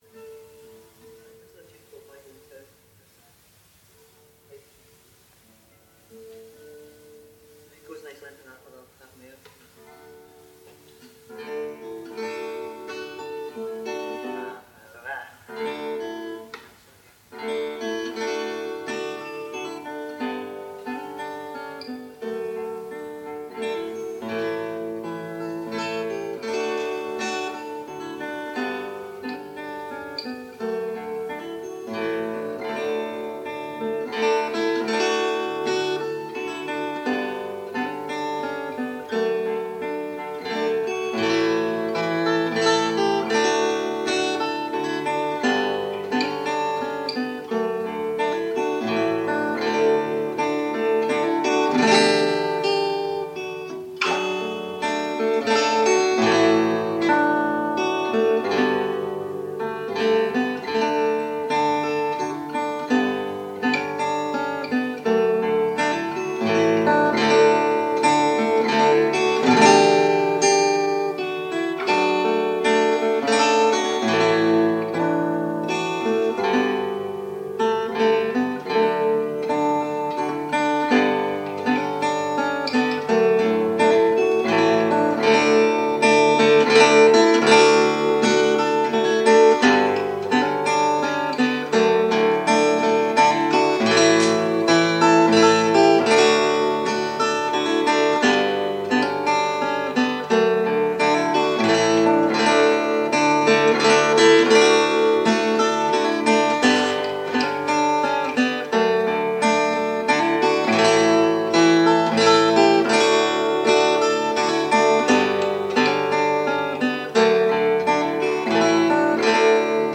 playing fiddle